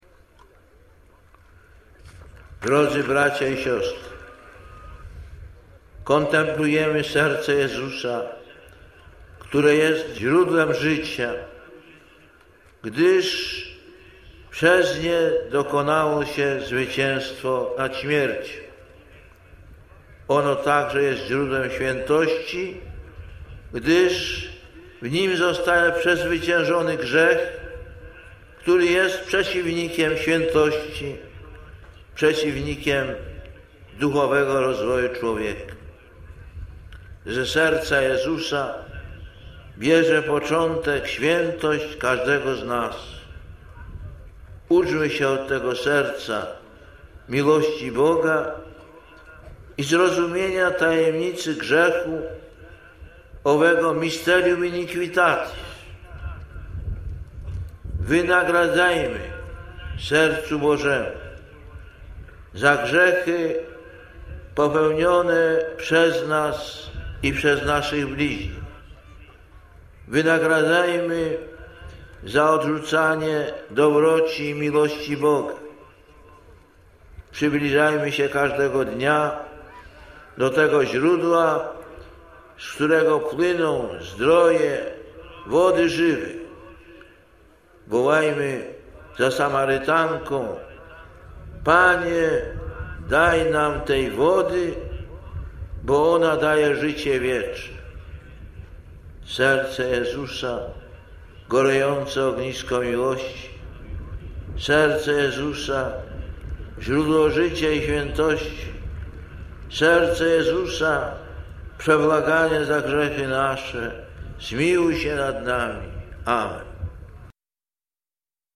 Lektor: Z homilii podczas nabożeństwa czerwcowego (Elbląg, 6.06.1999 –